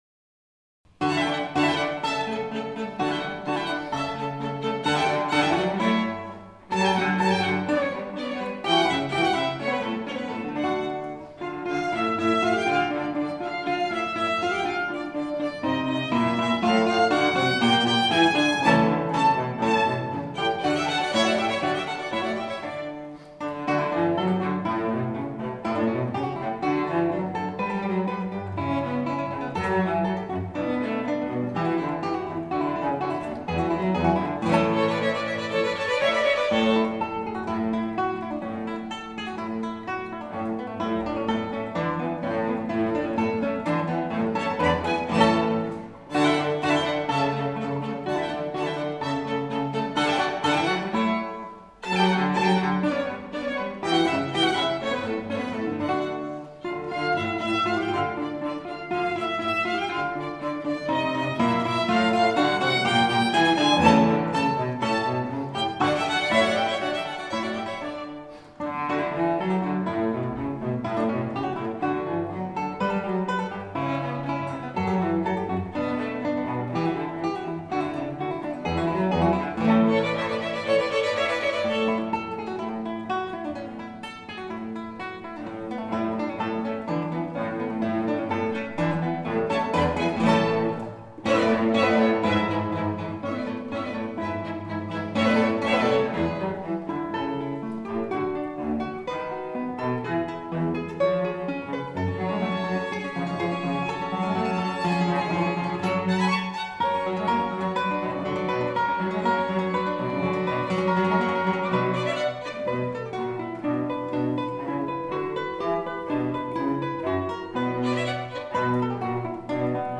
Capella Salonisti (Live Aufnahmen)
Gitarre
von Antonio Vivaldi | 1) Allegro